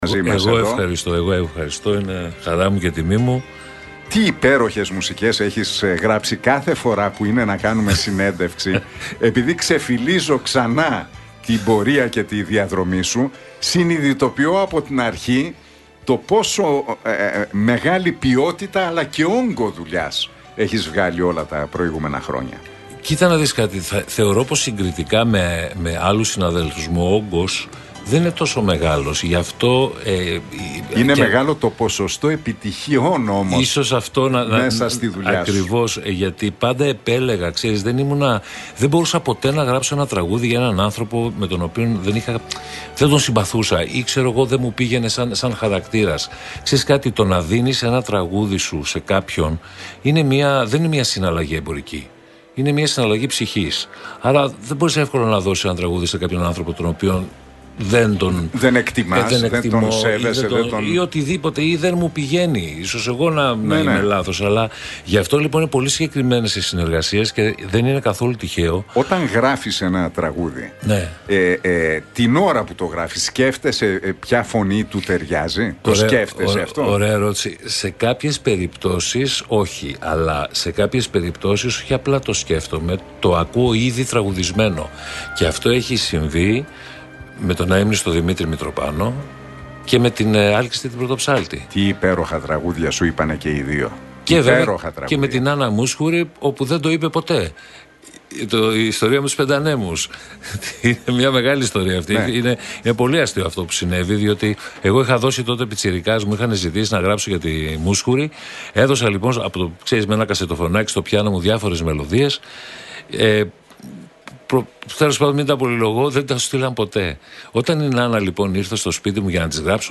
Μια άγνωστη ιστορία για τραγούδι του που δεν είπε η Νάνα Μούσχουρη αποκάλυψε στον Realfm 97,8 και τον Νίκο Χατζηνικολάου ο Στέφανος Κορκολής.